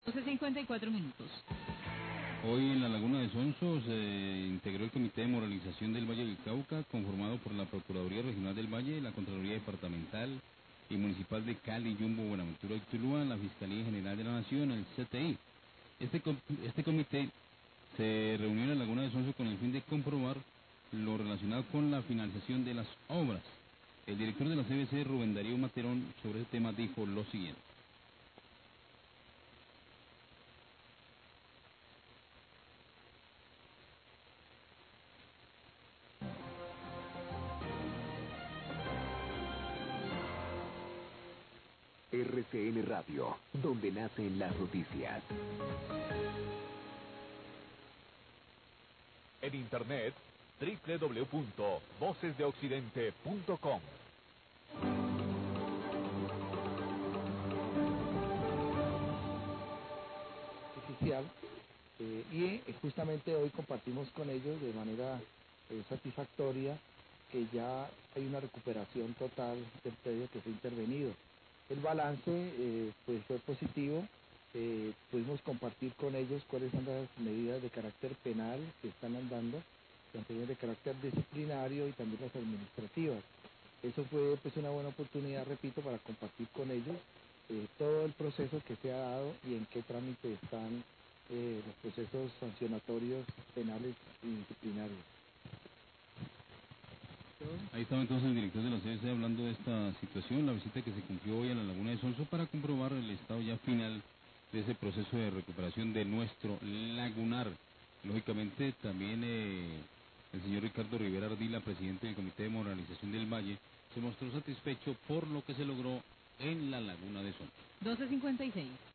Radio
Hoy en la Laguna de Sonso se integró el comité de moralización del Valle del Cauca, conformado por la Procuraduría Regional, Contraloría Departamental, Fiscalía y CTI, con el fin de comprobar lo relacionado con la financiación de las obras. Director de la CVC, Rubén Darío Materón, brinda un balance de la reunión.